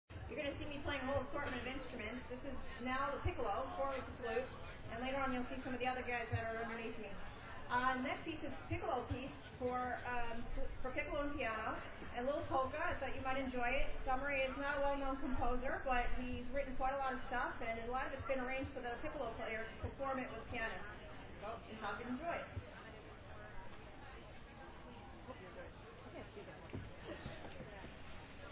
Classical Music Festival
Part of the Arts and Wine Festival
Courthouse Park, Cortland, NY USA